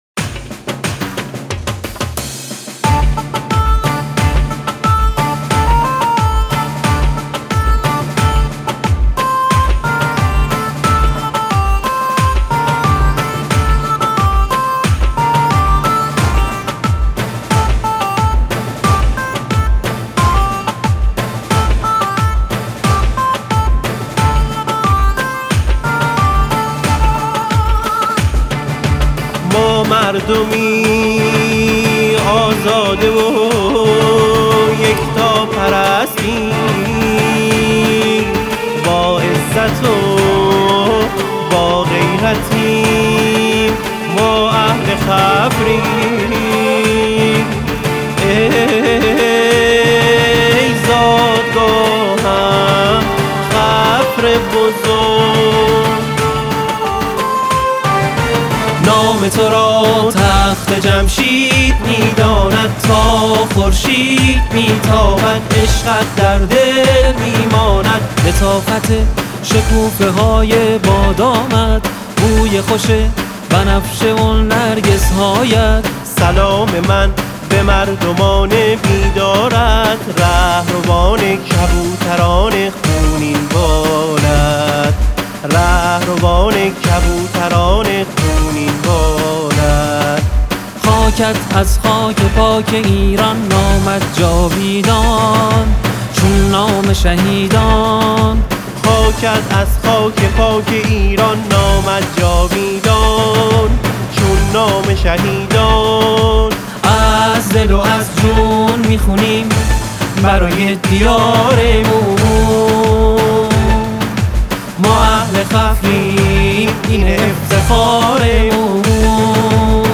در جشن ارتقای خفر به شهرستان اجرا شده است